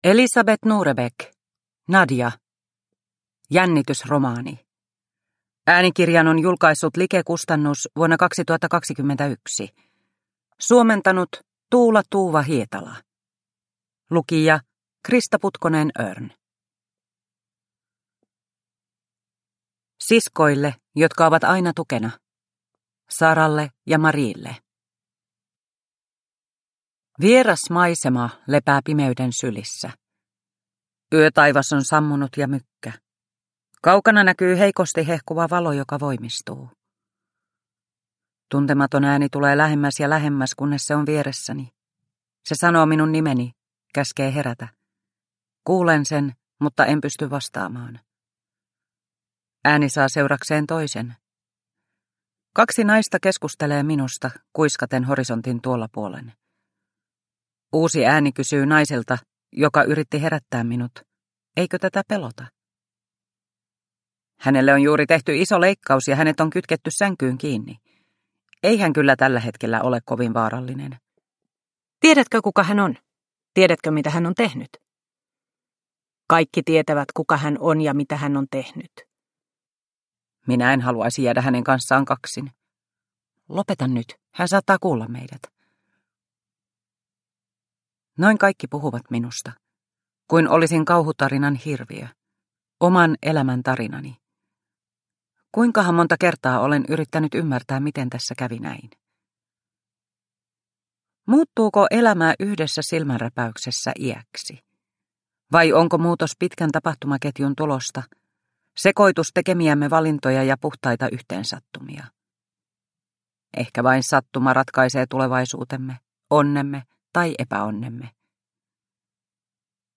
Nadia – Ljudbok – Laddas ner